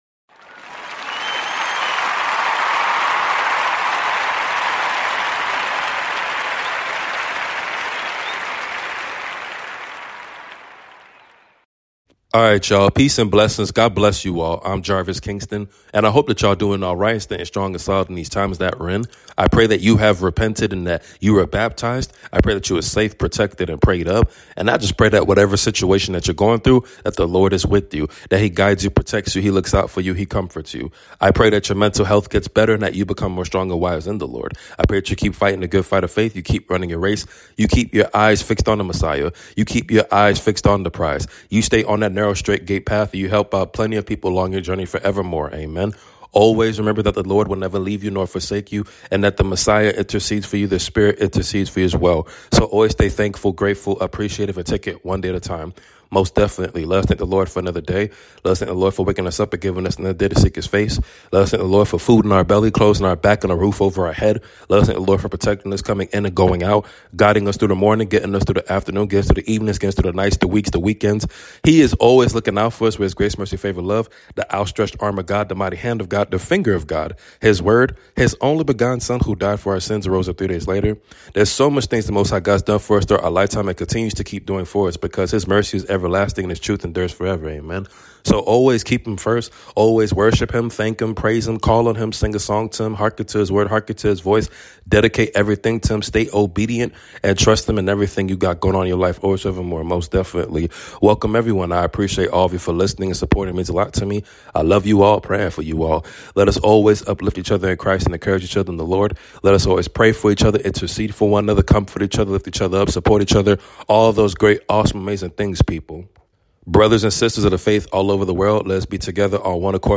Your Nightly Prayer 🙏🏾 Psalm 60:12